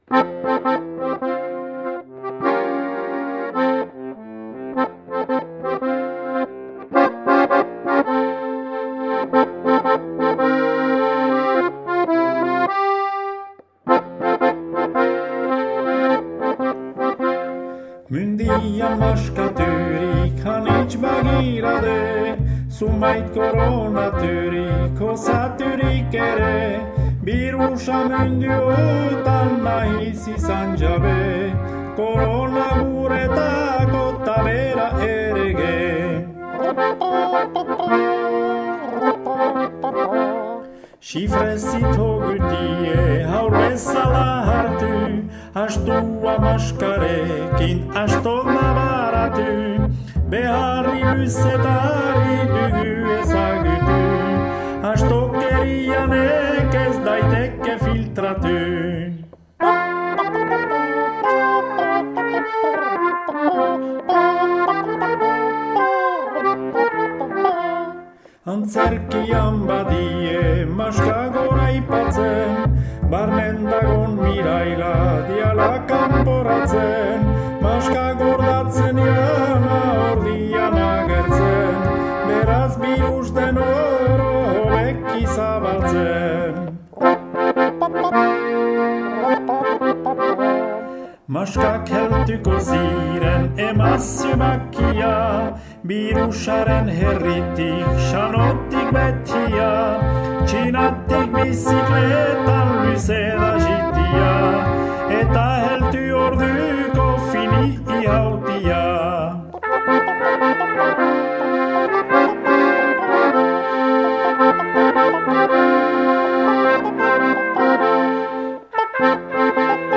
Bertso jarriak